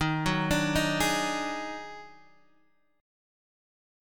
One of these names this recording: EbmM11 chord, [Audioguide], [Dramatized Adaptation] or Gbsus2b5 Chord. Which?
EbmM11 chord